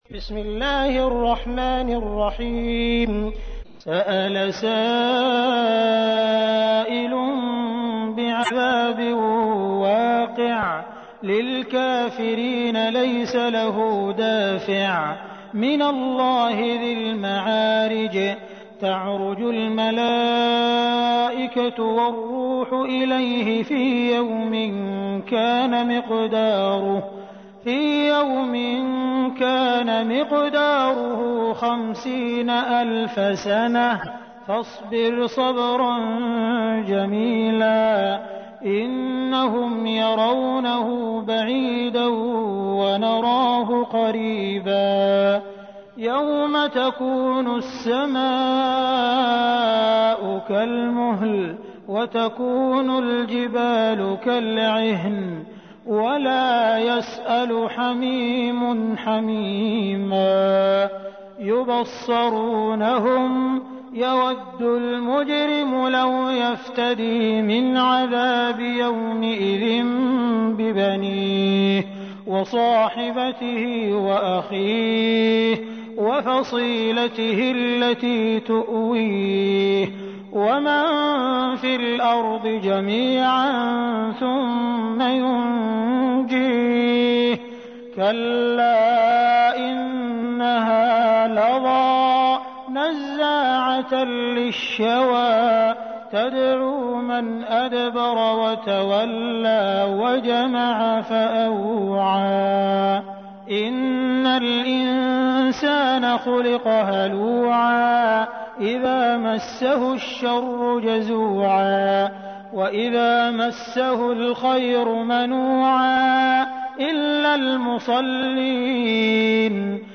تحميل : 70. سورة المعارج / القارئ عبد الرحمن السديس / القرآن الكريم / موقع يا حسين